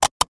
Nissan Sentra exterior point of view as gas cap and door opens and releases pressure.